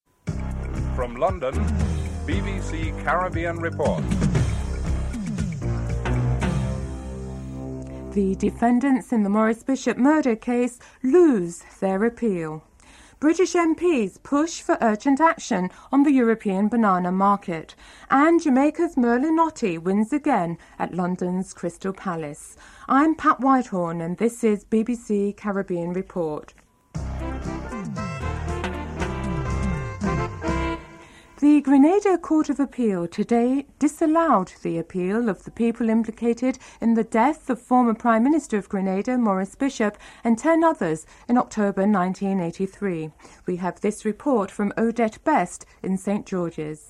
dc.description.tableofcontents3. Six British MPs submit an early day motion in the House of Commons to force the government to debate the European banana market, including giving guaranteed access to Caribbean producers. Interview with Conservative MP, Bowen Wells (05:21-09:01)en_US